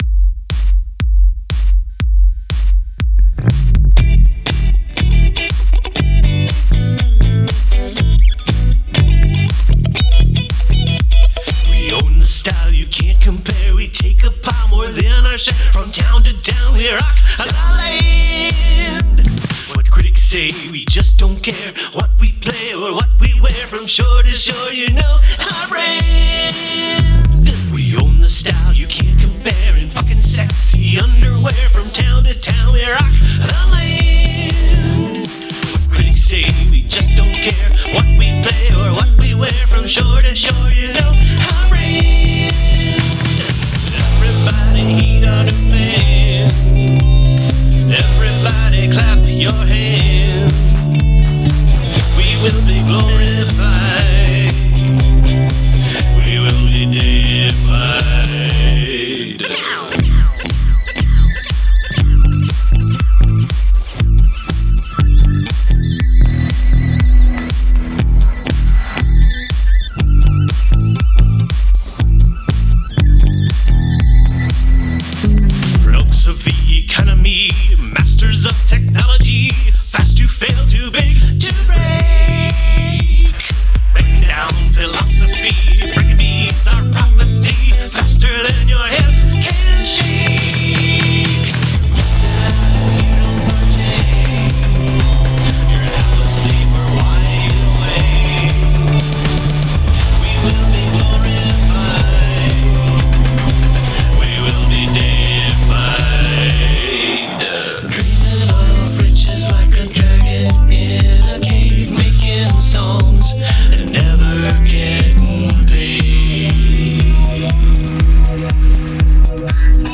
2. synth melody: B A D C D A B